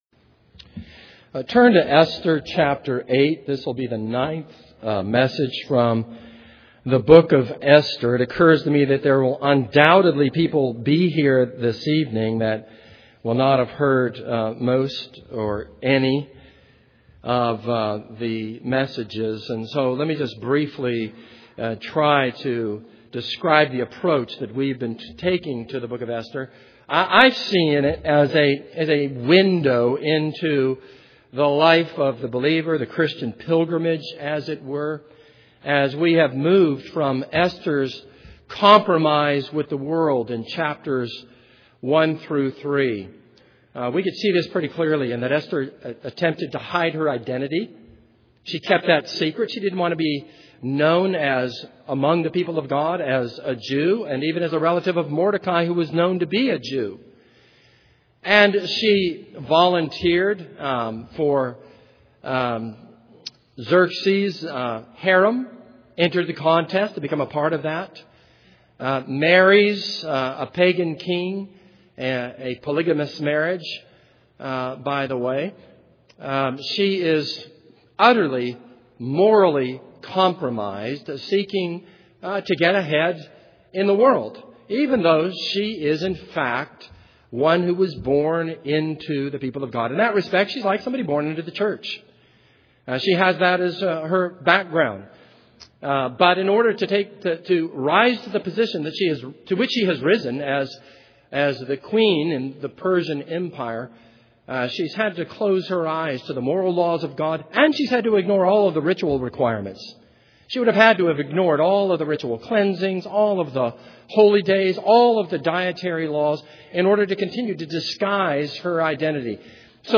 This is a sermon on Esther 8.